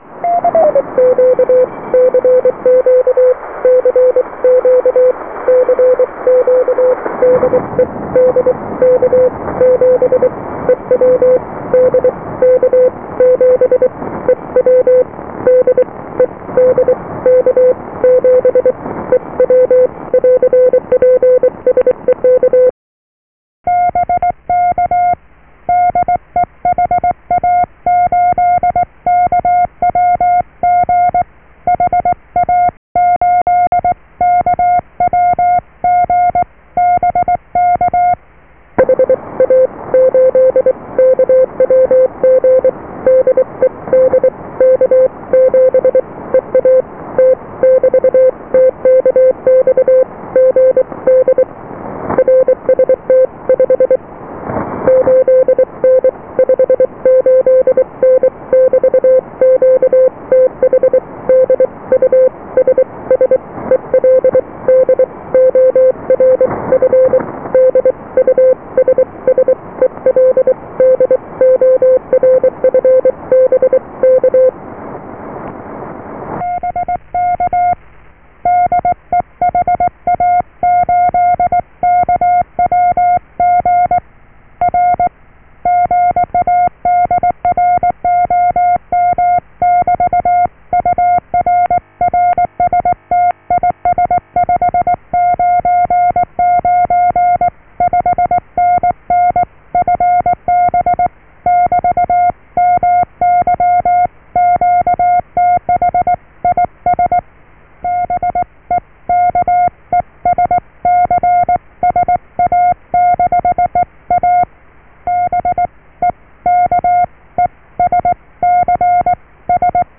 A felvételek valós megtörtént forgalmazást tartalmaznak.
CW
morze